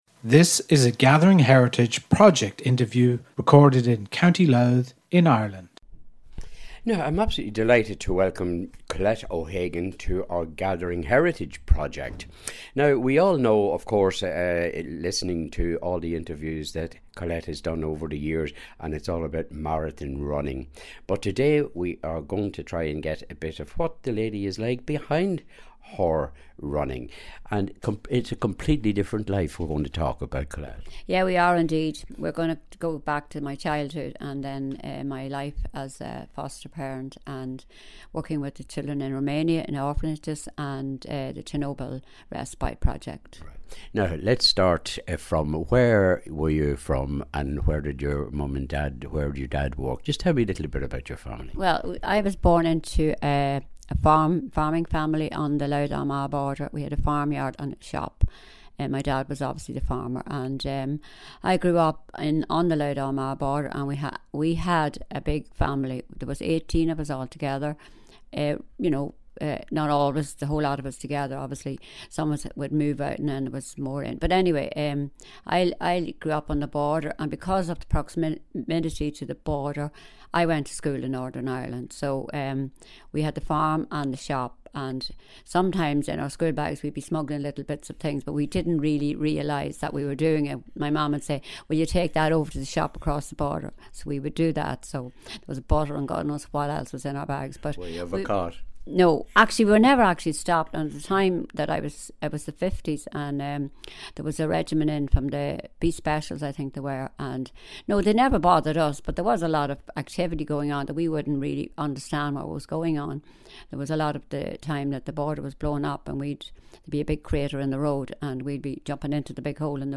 Recorded for the Gathering Heritage Project in Dundalk, Co. Louth in Ireland.